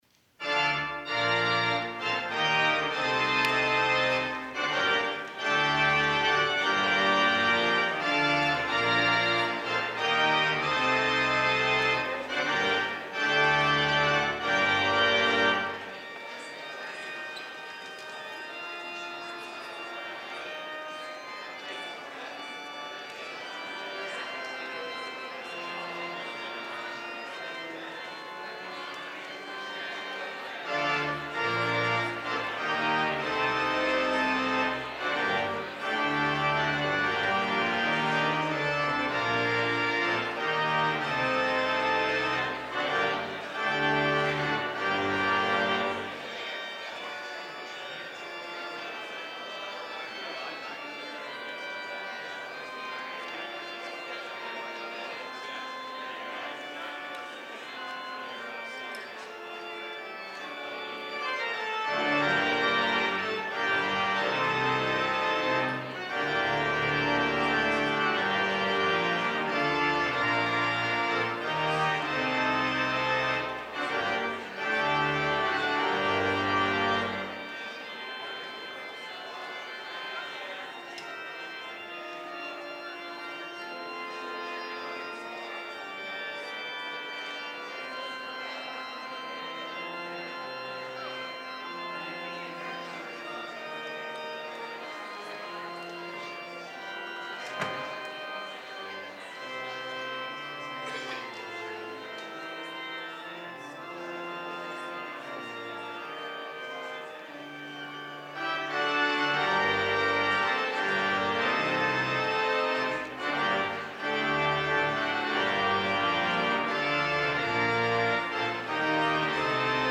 Selection: Chaconne in G Minor (Louis Couperin, 1626-1661)
organ